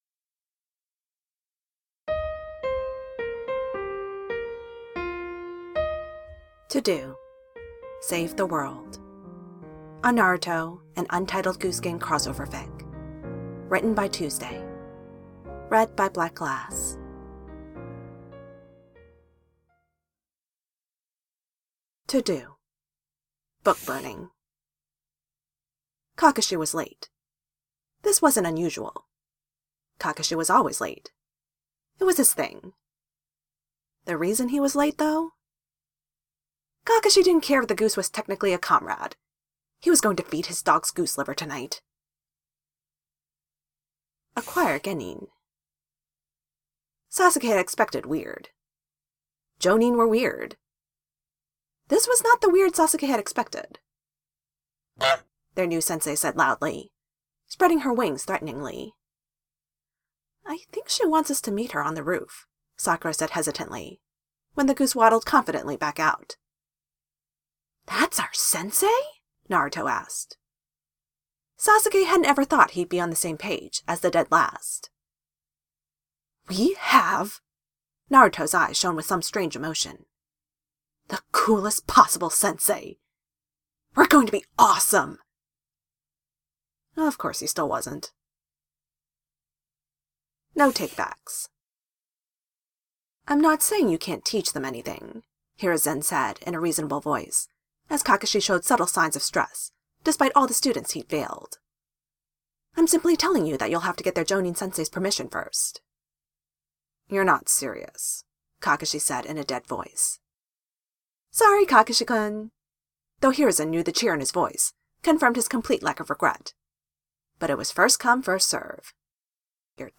Length (with music): 8:20
(Also, yes, I did pull the honk and cross out sound effects from the game. :P)